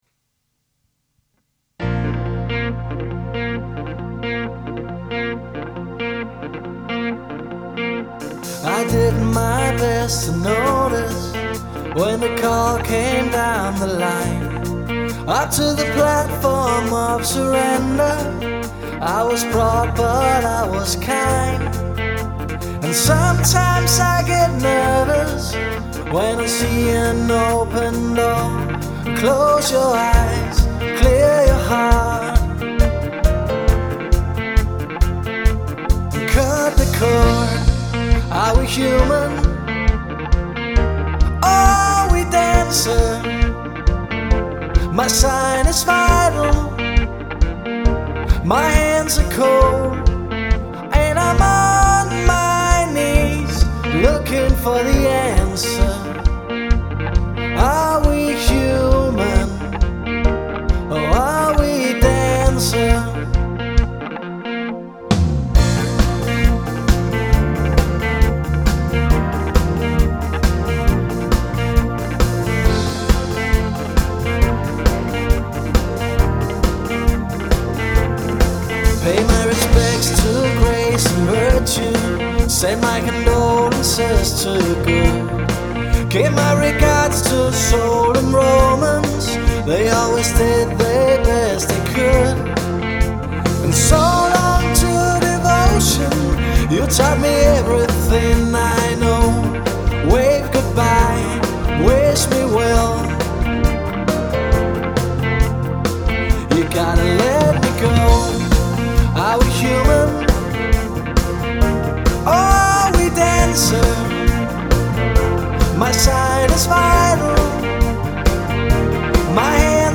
• Allround Partyband
• Coverband
• Rockband
• Duo eller trio